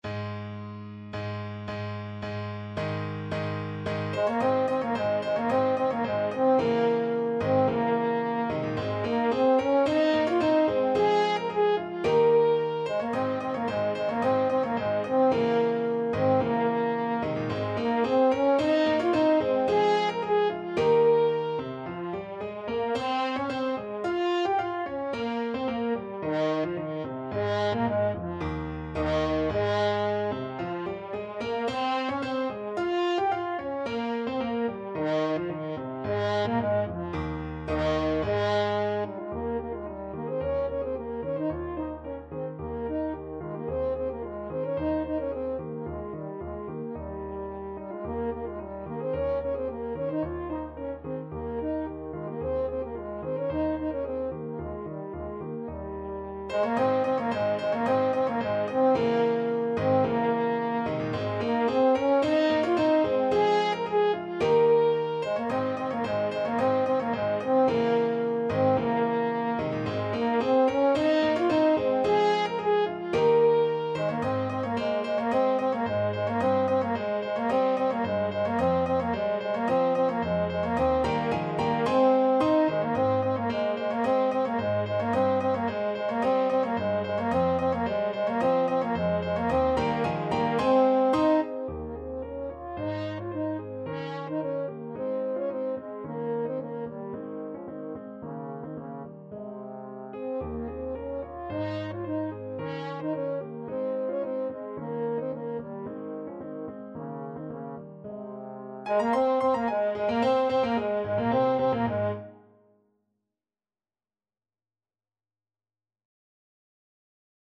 = 110 Allegro di molto (View more music marked Allegro)
2/2 (View more 2/2 Music)